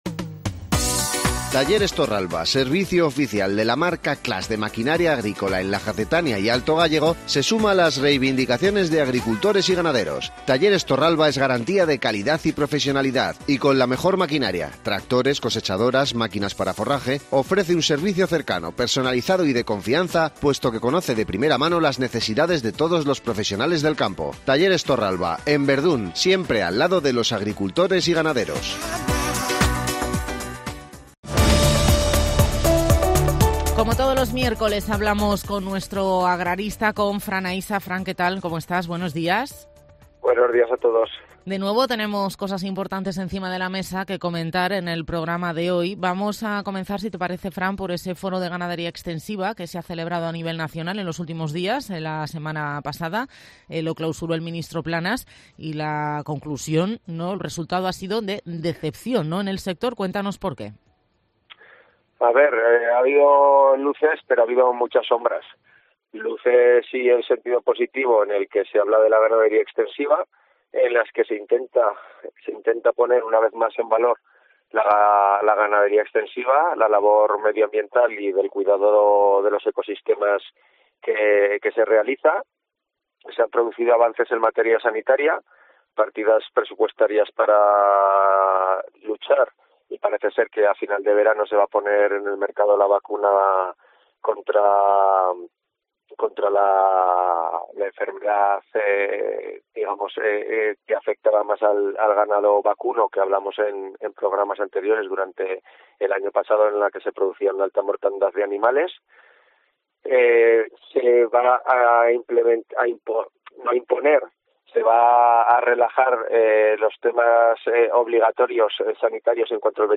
agricultor y ganadero en la Jacetania